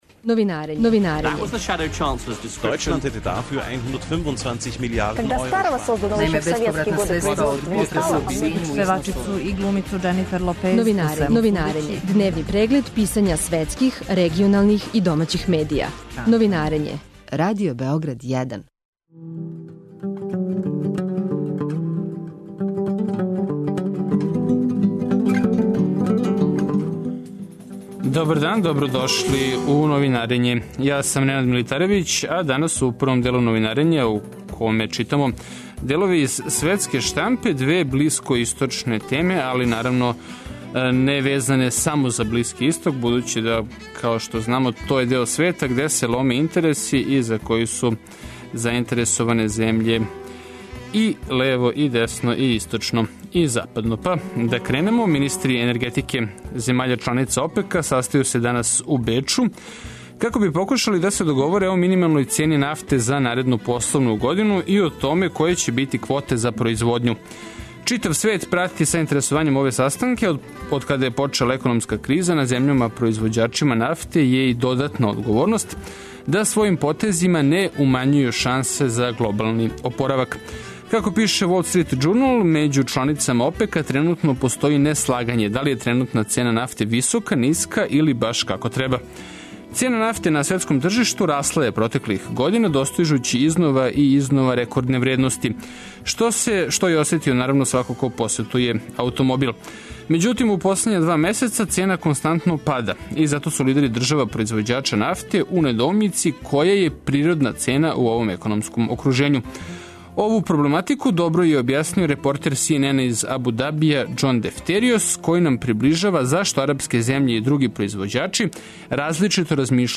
Преглед штампе у трајању од 15 минута. Чујте које приче су ударне овога јутра за највеће светске станице и листове, шта се догађа у региону и шта пише домаћа штампа.